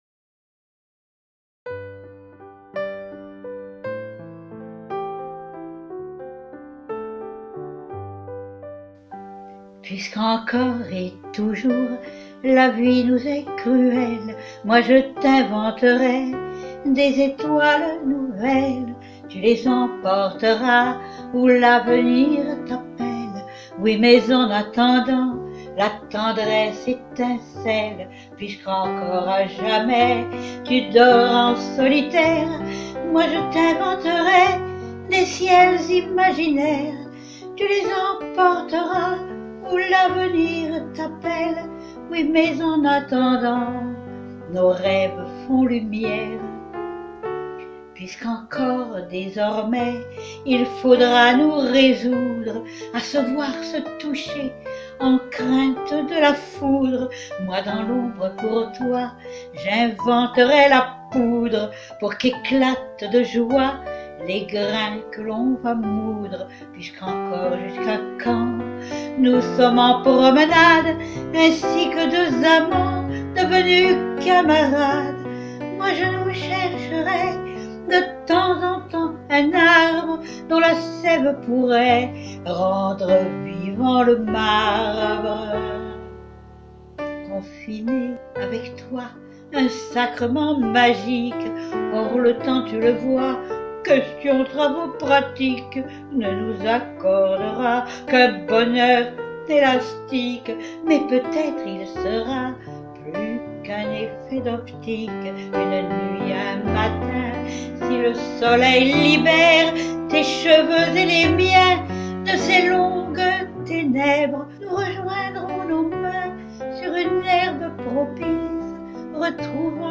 Goguette sur l’air de « Des caresses nouvelles »
Arrangement et accompagnement piano